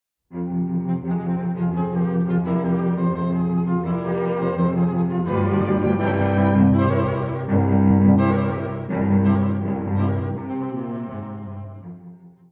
↑古い録音のため聴きづらいかもしれません！（以下同様）
1楽章の暗い暴走がまた戻ってきました。
冒頭の、意志の強い低音
稲妻のようなトリル（正確には16分）